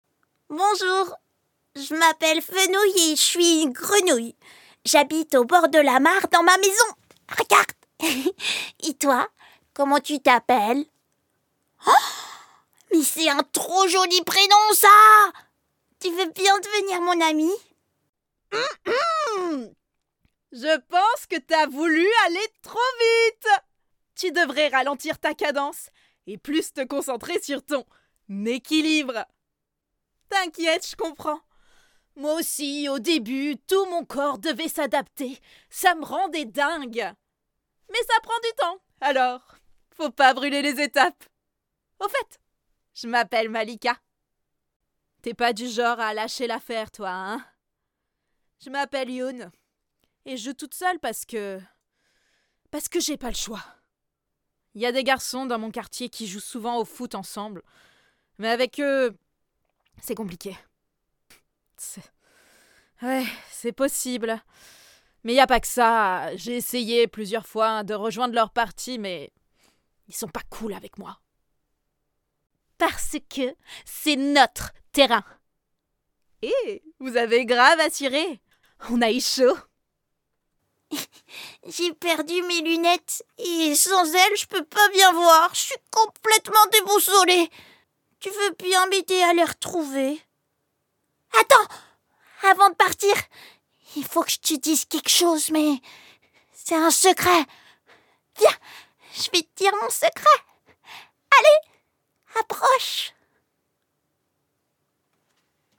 5 - 37 ans - Mezzo-soprano